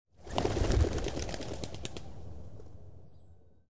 Звуки полета птиц
Голуби поднимаются ввысь